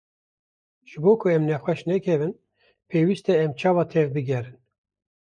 Pronounced as (IPA)
/peːˈwiːst/